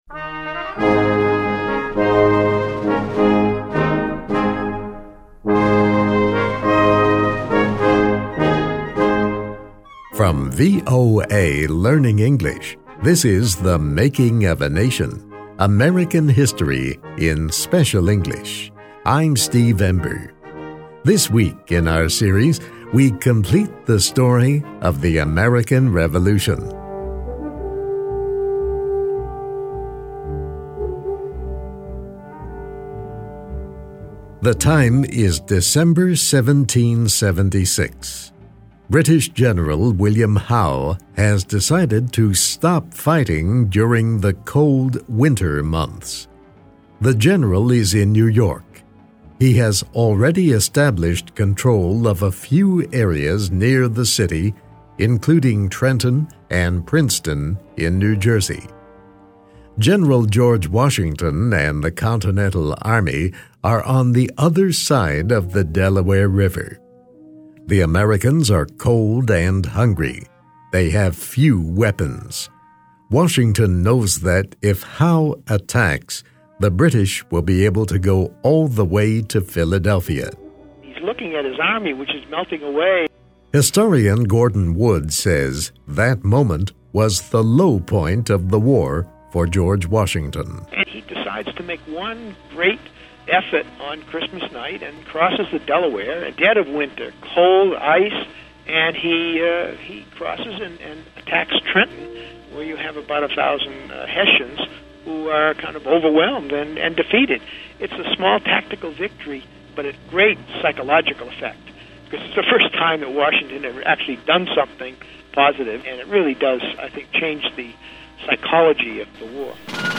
Read, listen and learn English with this story.